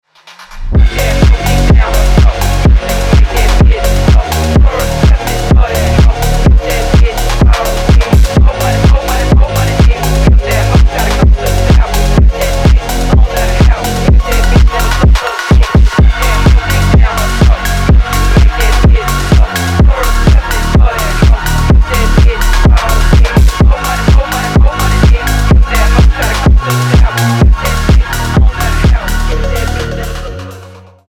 Стиль: phonk